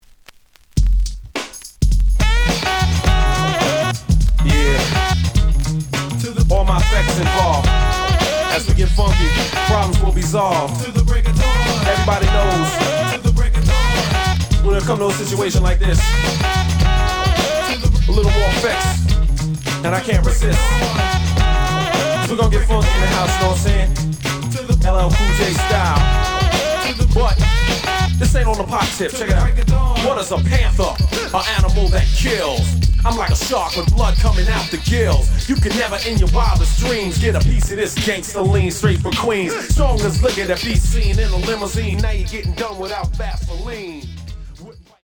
The audio sample is recorded from the actual item.
●Genre: Hip Hop / R&B
Slight periodic noise on A side, but almost good.